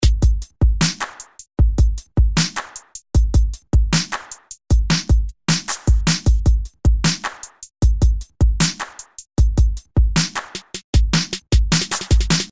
描述：鼓声与春风吹拂的低音相匹配
Tag: 154 bpm Hip Hop Loops Drum Loops 2.10 MB wav Key : Unknown